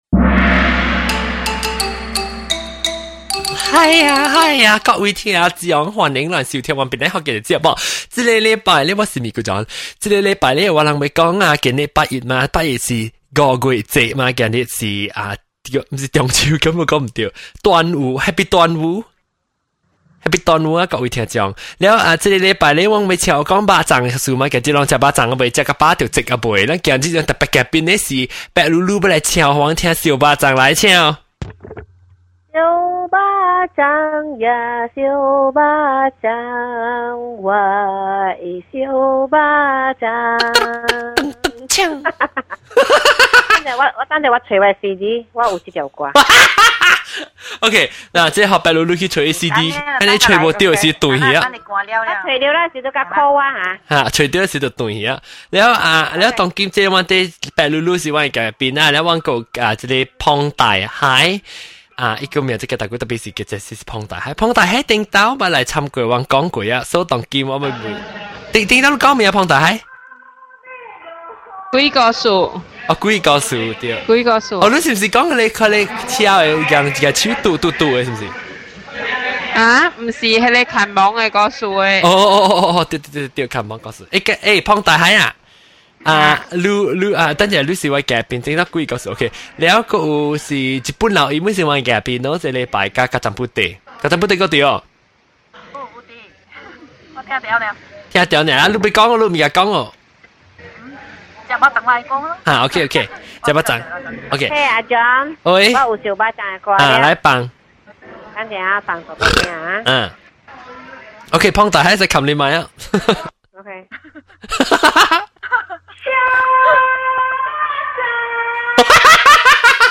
Listen to the ladies talk about how they had to fight to bak chang to bak chang’s secret recipe. Bak chang competition.